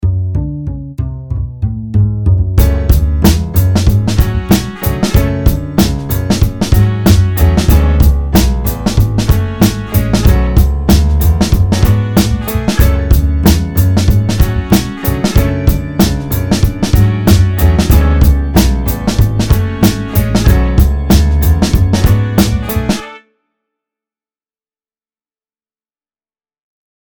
It's a try at jazz, with a jazz piano, a bass et cetera.
This track, recorded on May 9th, features a jazz piano and bass, showcasing an attempt at jazz composition.